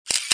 Index of /phonetones/unzipped/Samsung/GT-C3350/system/Camera sounds
Camera shot 1.wav